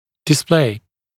[dɪs’pleɪ][дис’плэй]показ, демонстрация; показывать, демонстрировать